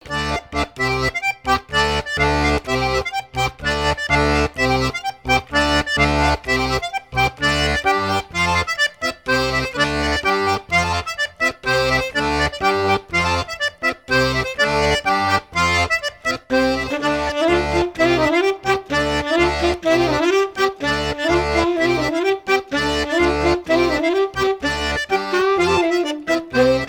danse : ronde à la mode de l'Epine
Pièce musicale éditée